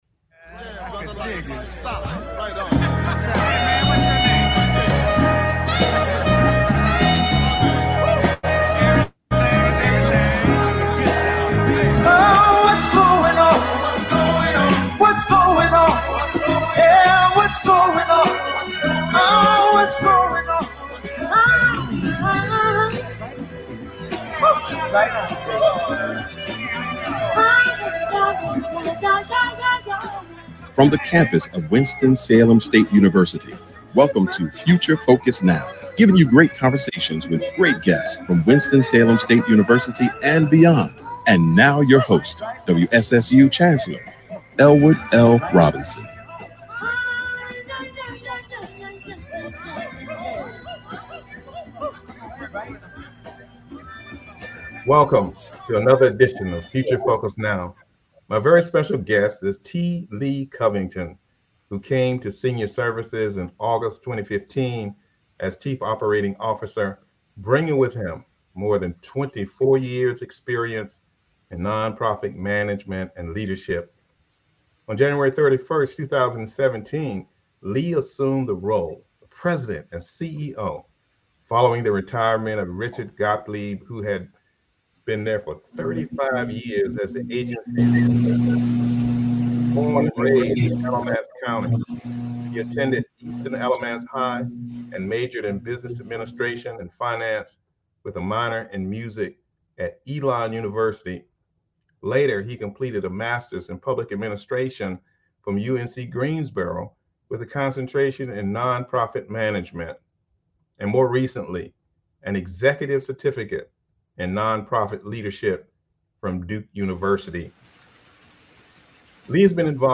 Future Focus is a one-hour public affairs talk show hosted by Winston-Salem State University's Chancellor Elwood L. Robinson.